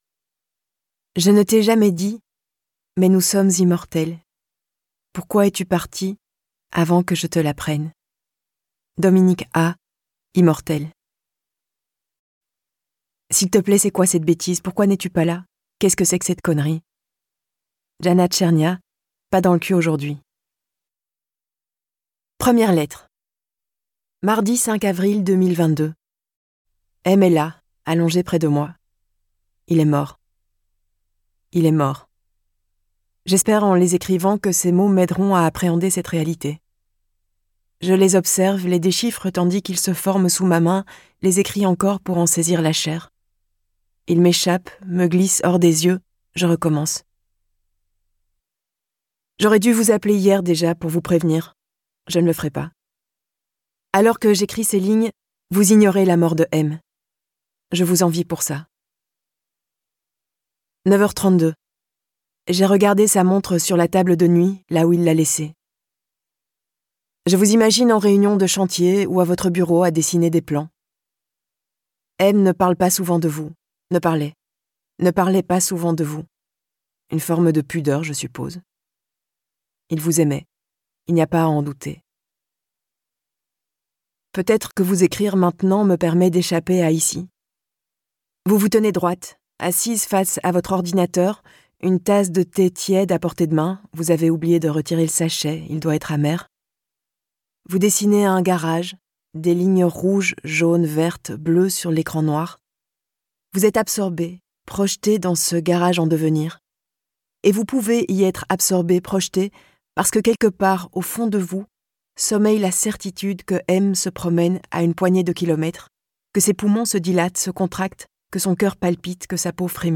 Click for an excerpt - Reste de Adeline DIEUDONNÉ
Get £2.55 by recommending this book 🛈 Une nouvelle facette d'Adeline Dieudonné révélée dans ce roman envoûtant. Lu par l'autrice Je ne suis pas certaine d'avoir pleinement saisi ce qui m'est arrivé, ni ce qui m'a conduite à agir comme je l'ai fait.